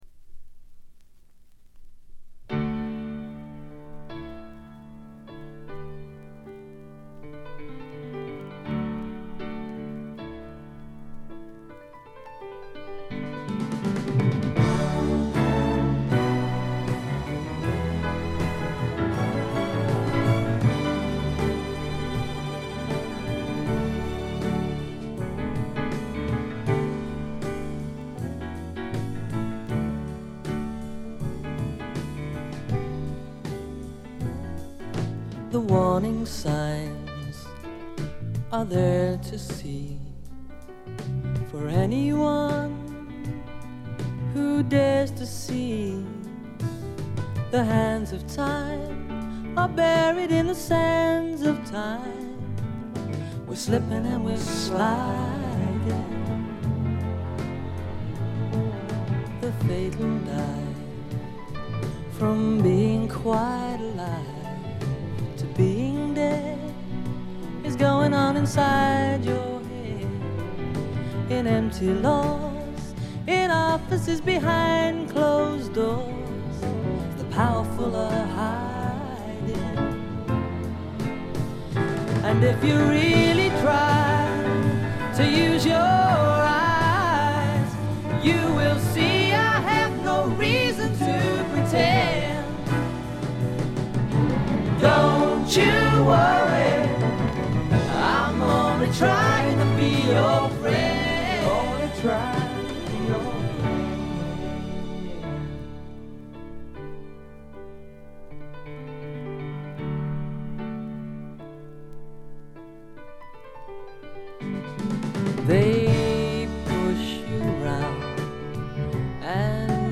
英国のポップ・デュオ
いかにも英国らしい繊細で哀愁感漂う世界がたまらないです。
試聴曲は現品からの取り込み音源です。